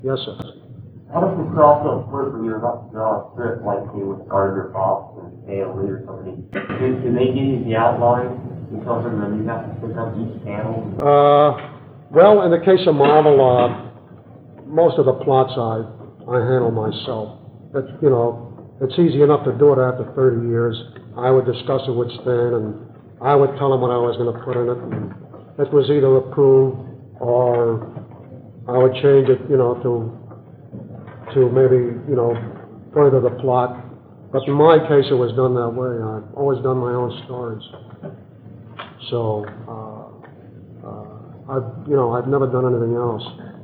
In researching this page I tracked down every Kirby interview I could find, and he has always been consistent: he created the plots, and always did it that way (at least with regard to Marvel). the best early interview I can find is from the 1970 comic convention a few months after he left Marvel.
(the audio is poor at the start, but Kirby's answer is clear)